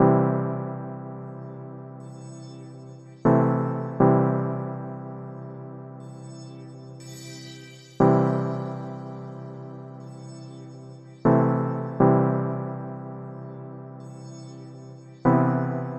Tag: 120 bpm Ambient Loops Pad Loops 2.69 MB wav Key : C